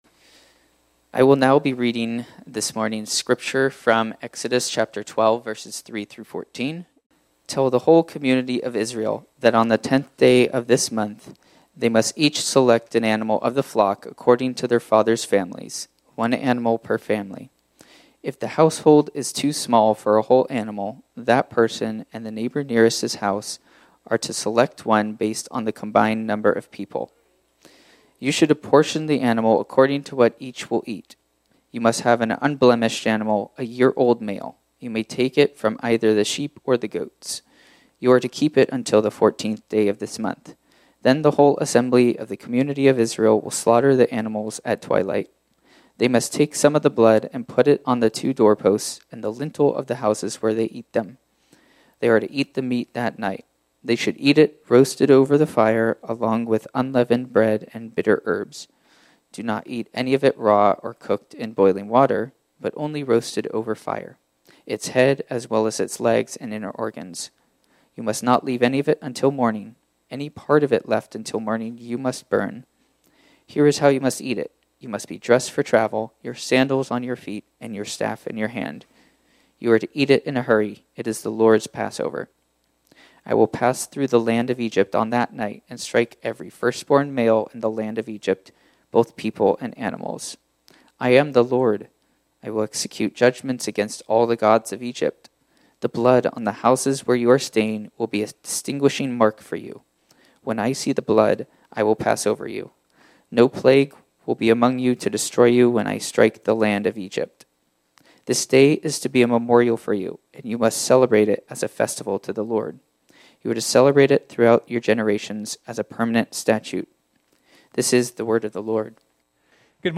This sermon was originally preached on Sunday, February 16, 2025.